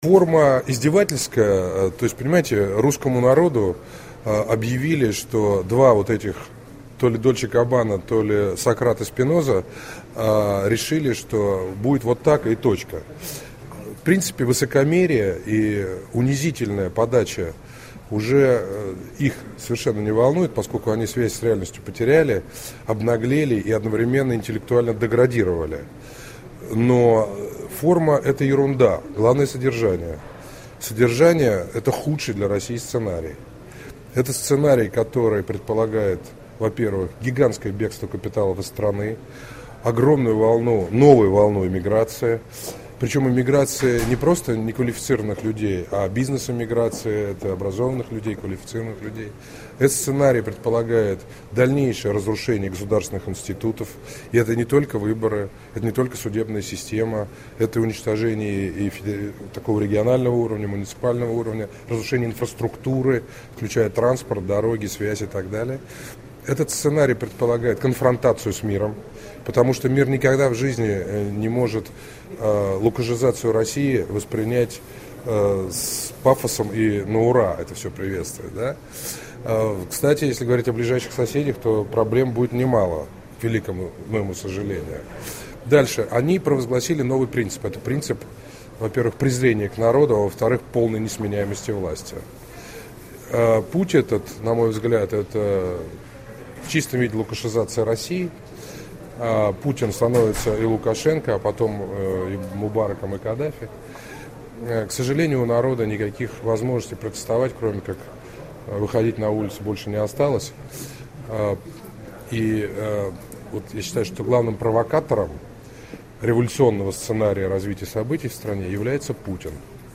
Второй съезд партии народной свободы. Немцов о рокировке
Второй съезд Партии народной свободы проходил 24 сентября в гостинице Гамма-Измайловская в Москве. 122 делегата из 47 регионов страны решали организационные вопросы и определяли стратегию партии на предстоящих парламентских и президентских выборах.